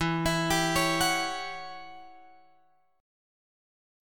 E Minor 6th Add 9th